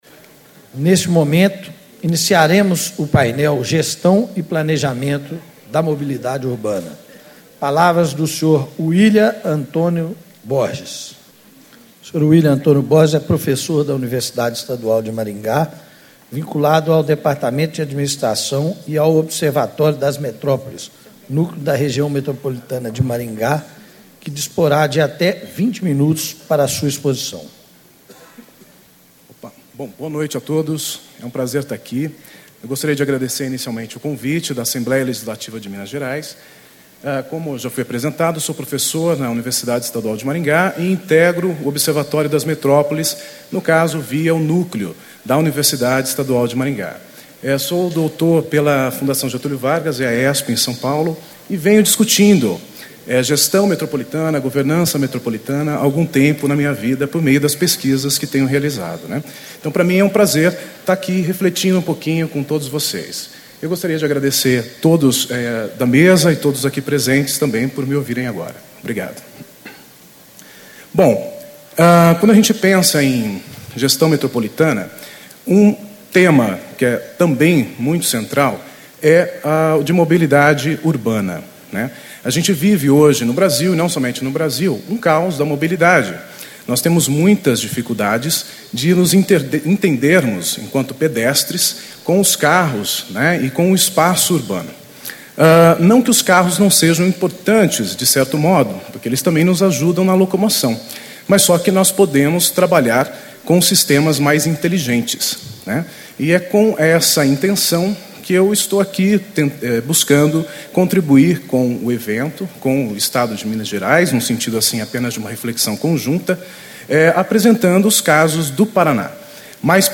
Encontro Estadual do Fórum Técnico Mobilidade Urbana - Construindo Cidades Inteligentes
Palestra